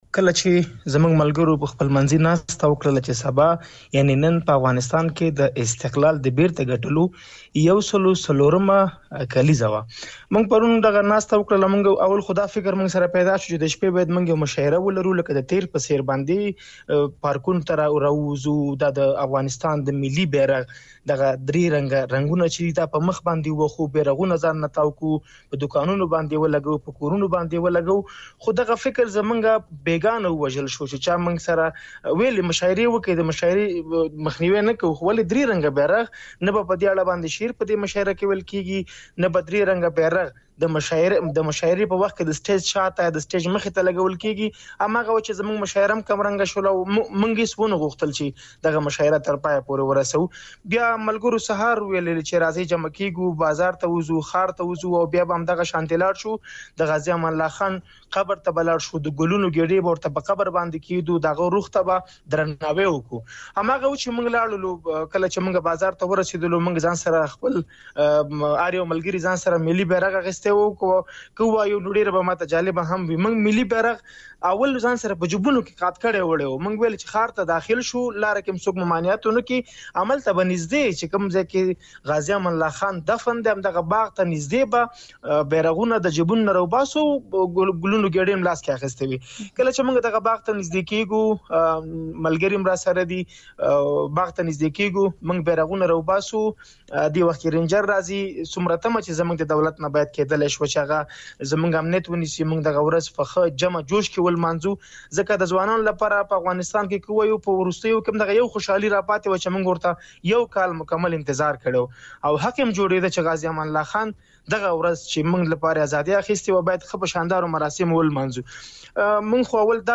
مرکه
په ننګرهار کې د خپلواکۍ ورځې له نمانځنې منع شوي ځوان سره مرکه